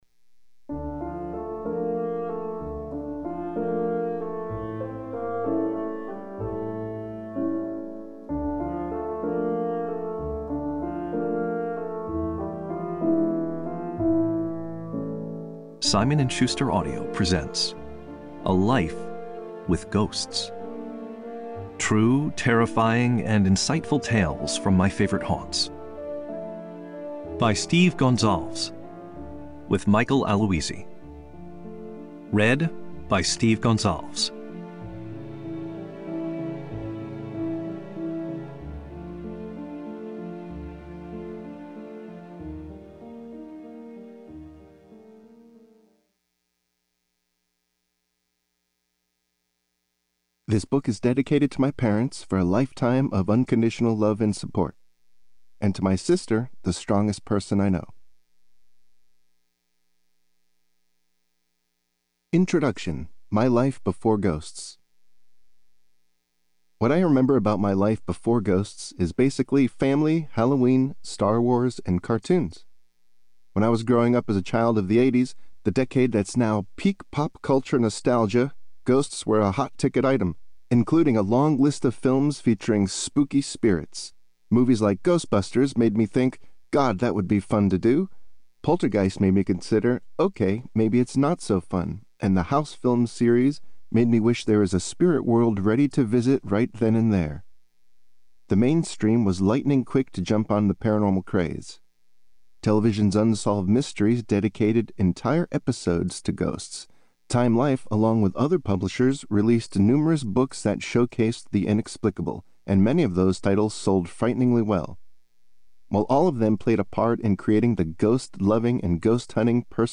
Goosebumps ensued during this recording for Simon & Schuster Audio.